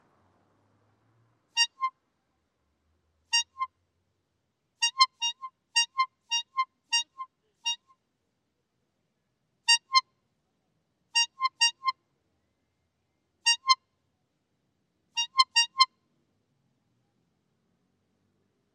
Tag: 氛围 trompeta 氛围 声音